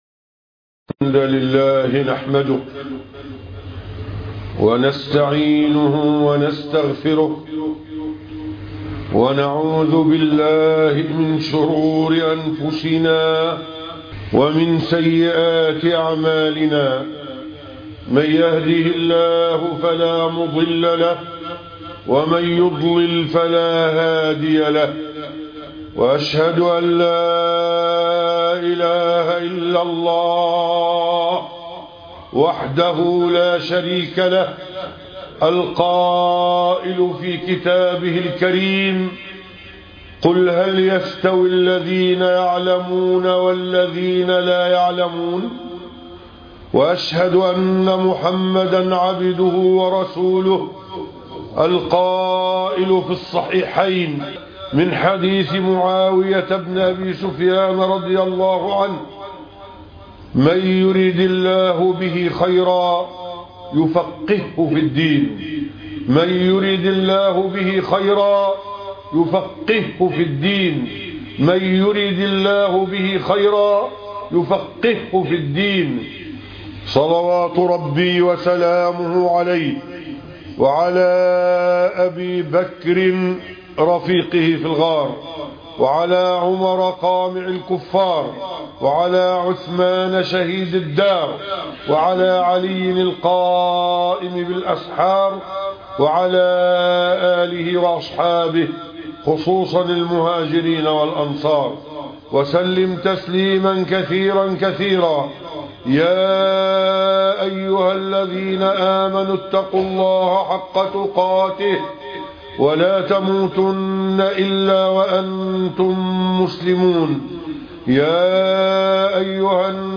تذكير الثقلين بمشروعية المسح على الخفين 3- خطبة الجمعة